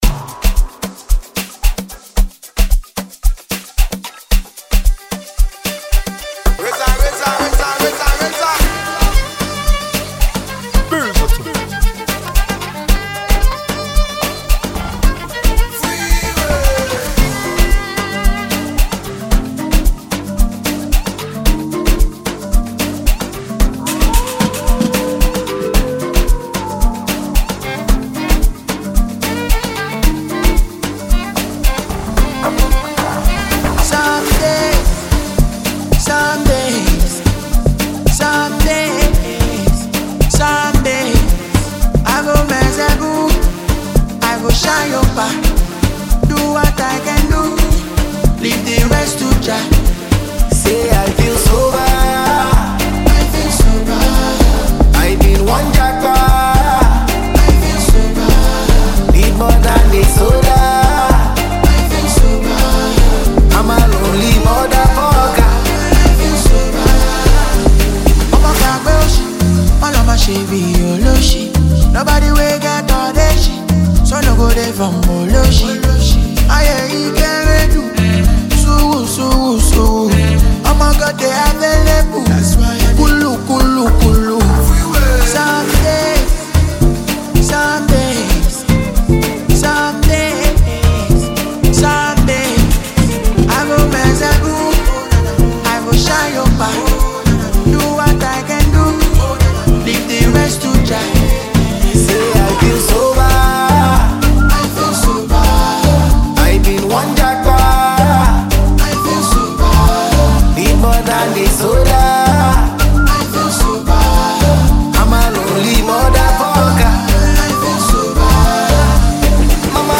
Renowned talented Nigerian singer